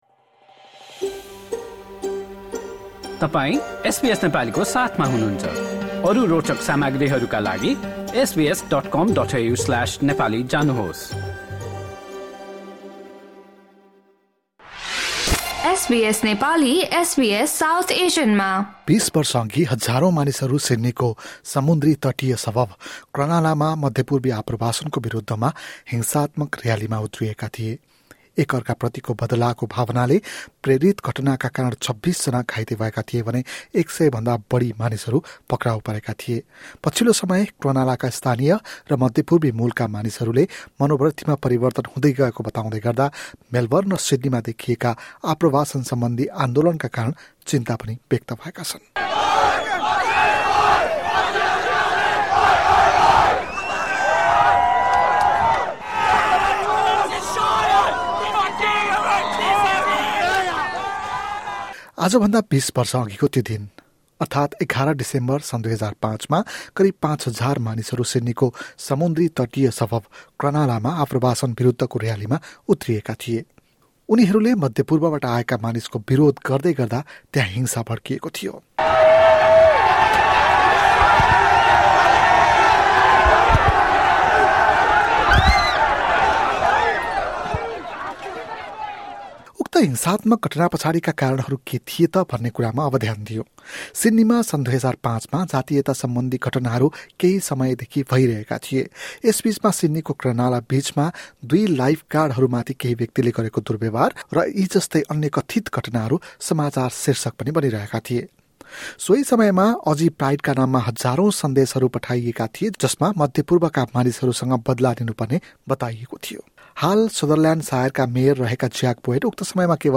दुई दशक अगि हजारौँ मानिसहरू सिड्नीको समुन्द्री तटीय सबर्ब क्रनलामा मध्य पूर्वी पृष्ठभूमिका मानिसहरूको विरुद्धमा भन्दै हिंसात्मक प्रदर्शनमा उत्रिएका थिए। एक अर्का प्रतिको बदलाको भावनाले प्रेरित घटनाका कारण करिब २६ जना घाइते भएको र एक सय भन्दा बढी मानिसहरू पक्राउ परेको बताइएको थियो। एक रिपोर्ट।